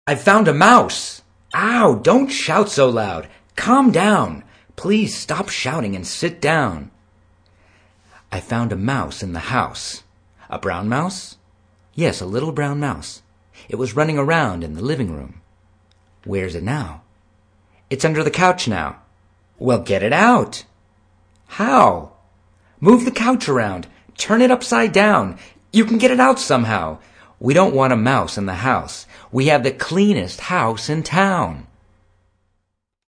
Hi Classes – below are recordings of myself reading the dialogs you have chosen. Listen to the examples and practice with the stress I use.